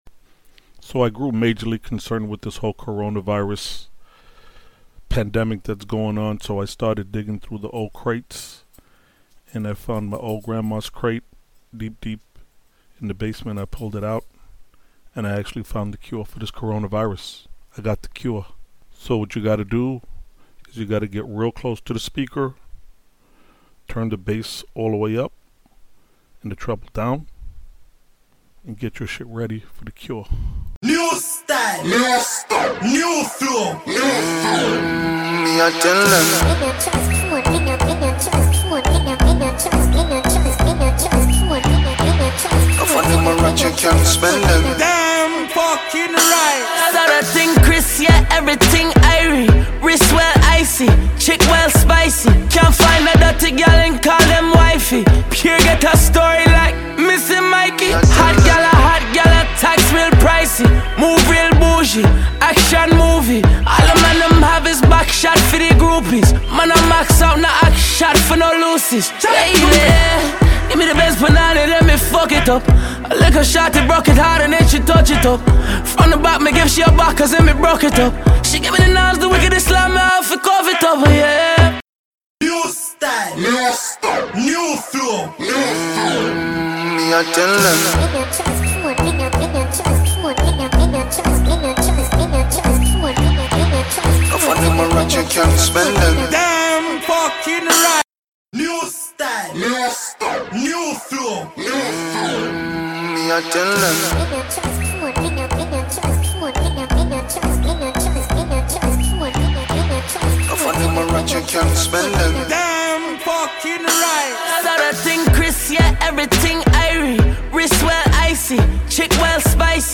Ep 41- The cure for the CoronaVirus -Deep Dive into Old school Reggae Dance Hall Beats - Listen and feel the healing through the speakers